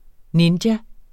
Udtale [ ˈnindja ]